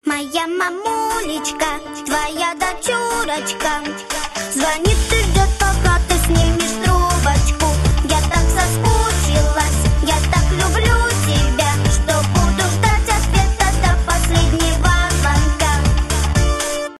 детский голос